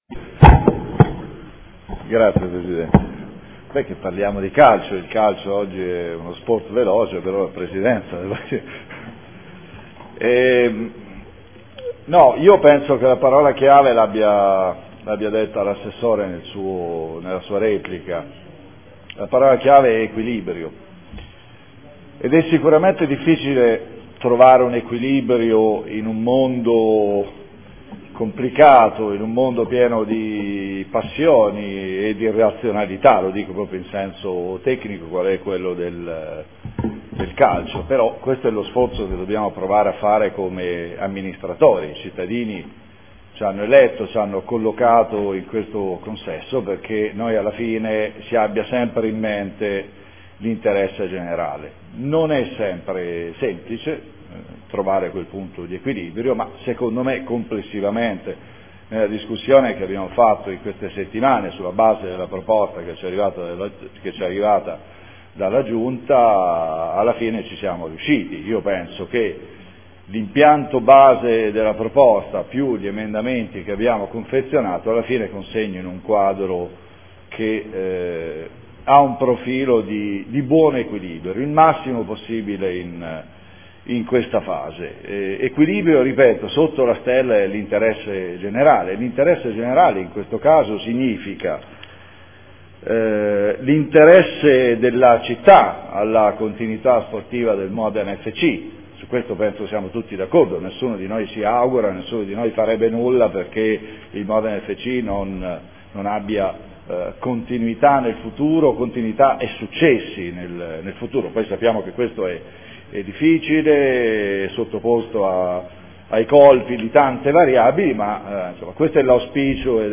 Convenzione per la gestione dello Stadio comunale Alberto Braglia al Modena FC S.p.a. – Modificazioni e prolungamento durata. Dichiarazione di voto.